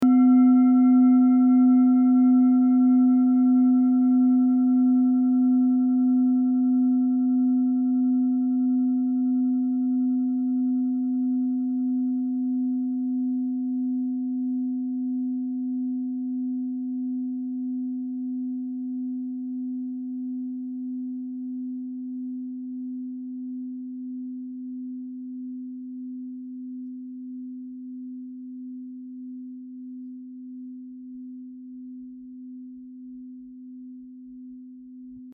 Klangschalen-Typ: Bengalen und Tibet
Klangschale 4 im Set 5
Klangschale Nr.4
(Aufgenommen mit dem Filzklöppel/Gummischlegel)
klangschale-set-5-4.mp3